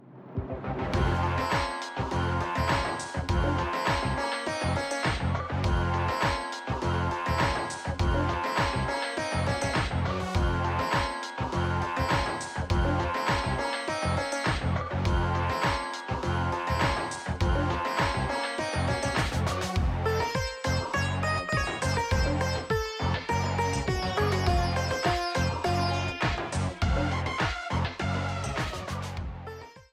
A yellow streamer theme
Ripped from the game
clipped to 30 seconds and applied fade-out